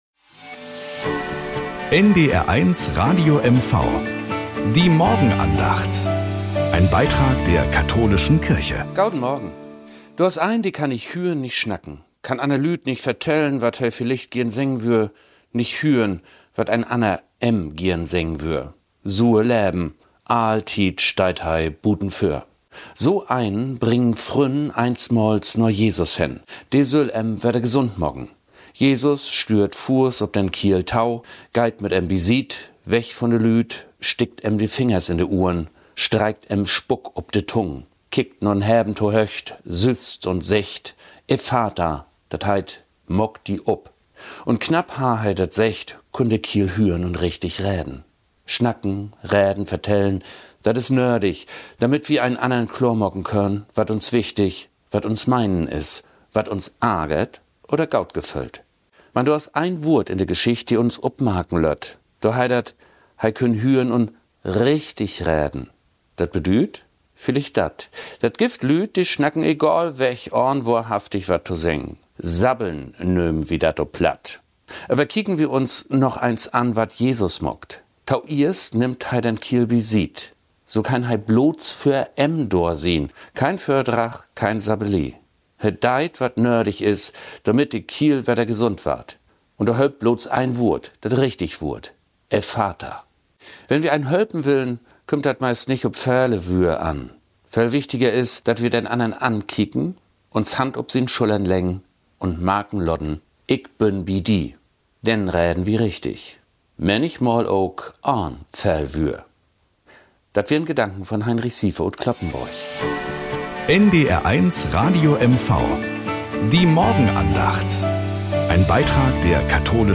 Nachrichten aus Mecklenburg-Vorpommern - 24.05.2025